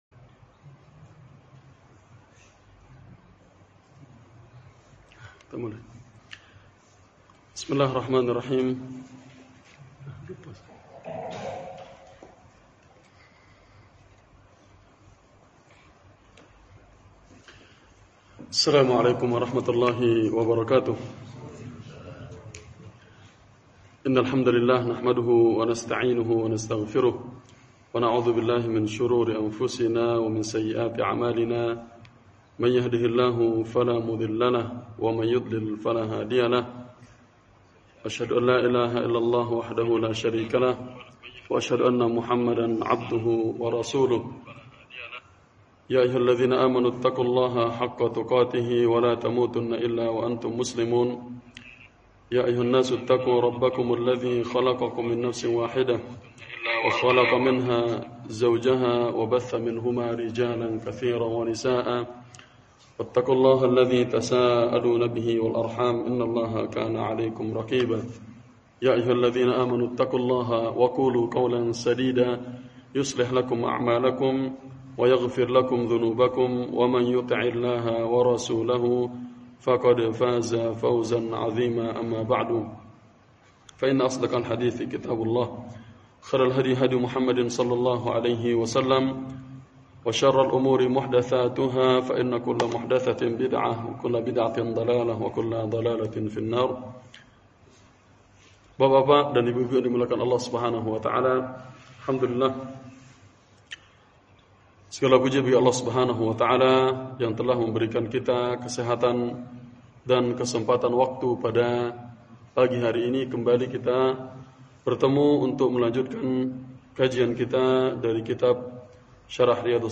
Daurah Al-Khor Sabtu Pagi – Masjid At-Tauhid